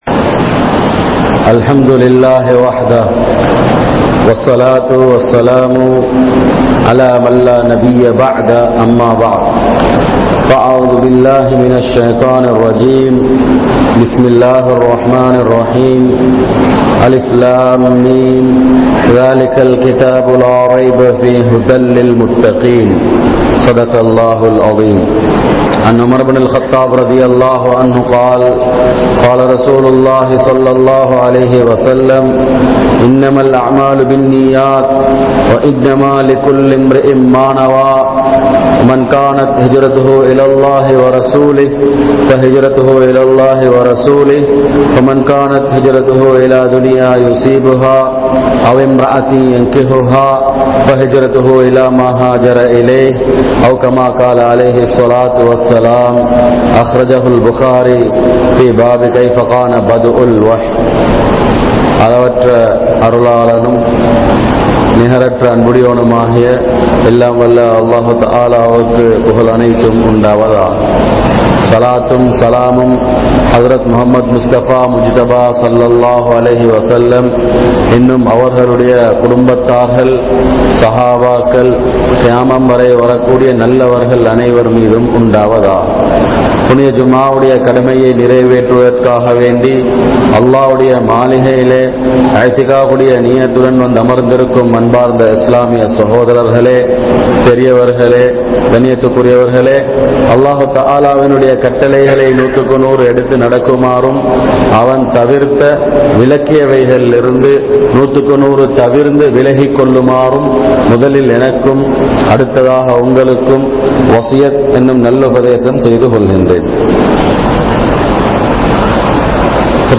Islam koorum Manitha Neayam (இஸ்லாம் கூறும் மனிதநேயம்) | Audio Bayans | All Ceylon Muslim Youth Community | Addalaichenai
Muhiyaddeen Grand Jumua Masjith